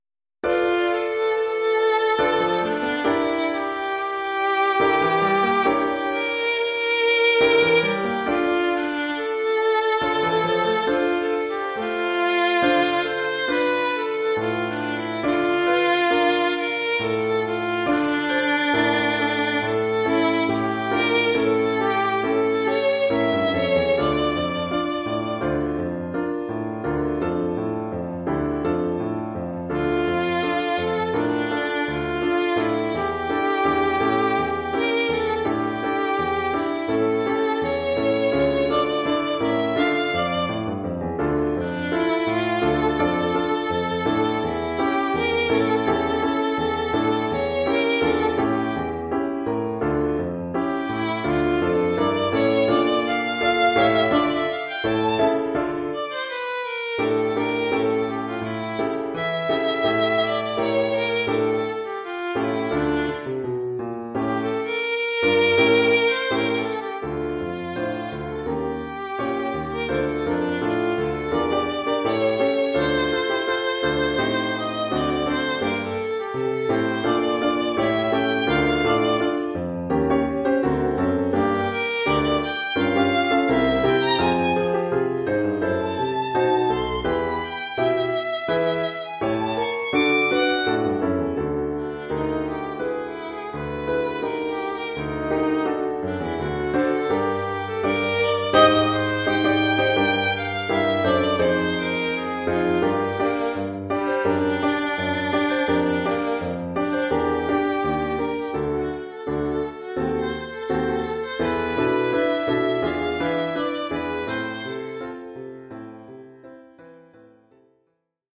Oeuvre pour violon et piano..